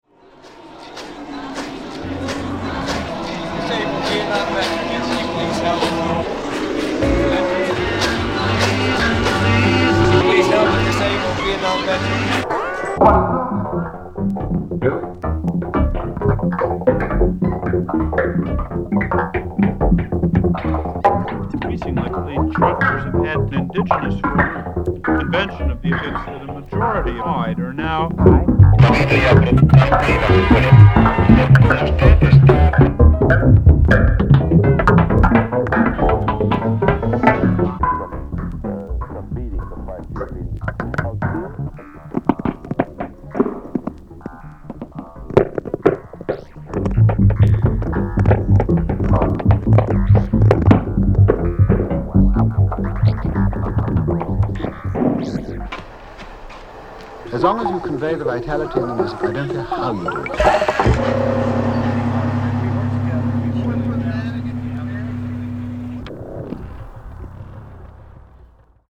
ミュージックコンクレート